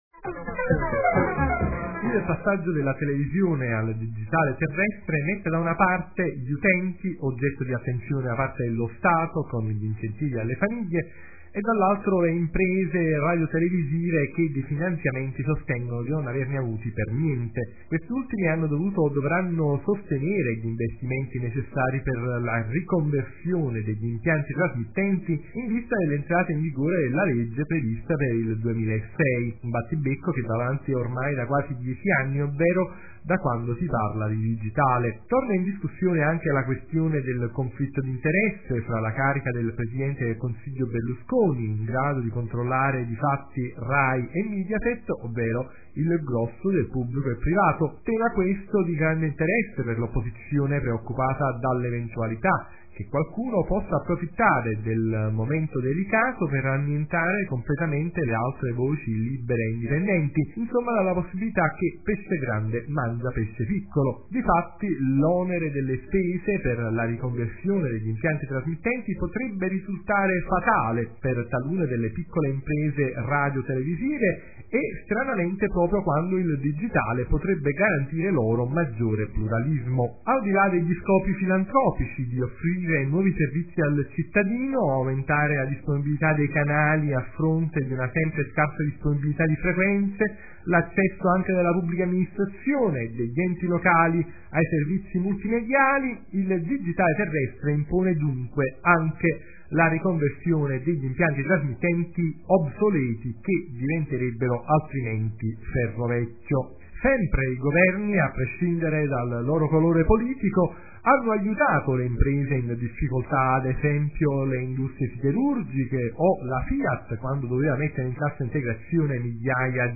Oggi ci stiamo occupando del passaggio della televisione al digitale terrestre, con una serie di interviste raccolte nei giorni  scorsi ad u convegno itinerante promosso  dell’’Istituto per lo studio dell’Innovazione nei media e per la Multimedialità ISIMM, la Fondazione Bordoni e i Corecom di Lazio, Campania e Sicilia.